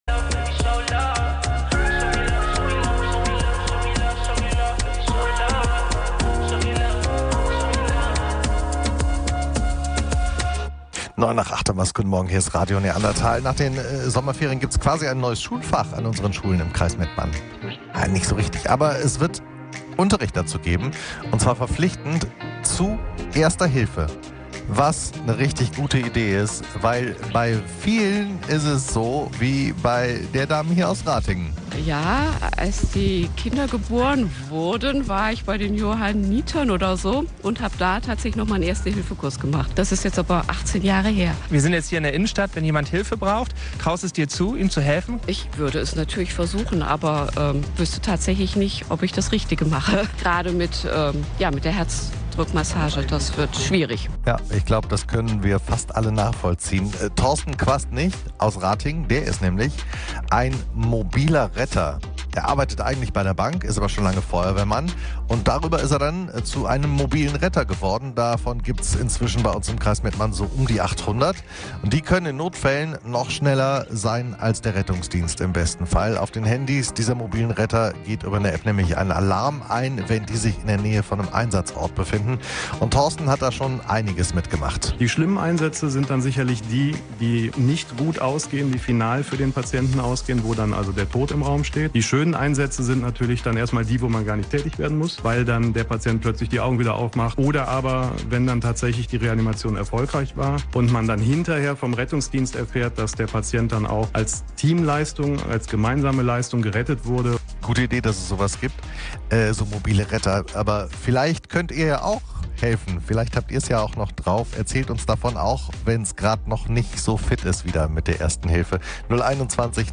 Wir haben mit einem Mobilen Retter gesprochen und Interessantes erfahren.